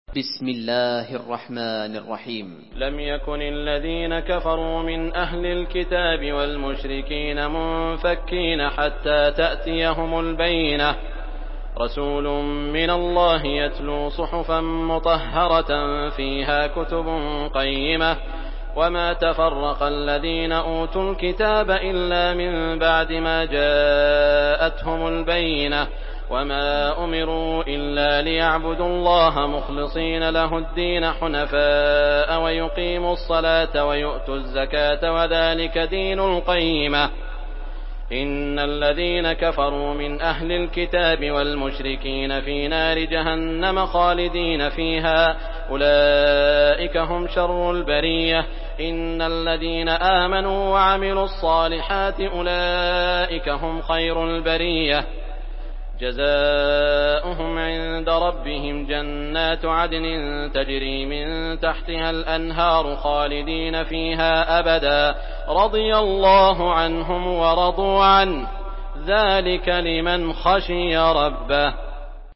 Surah Al-Bayyinah MP3 by Saud Al Shuraim in Hafs An Asim narration.
Murattal Hafs An Asim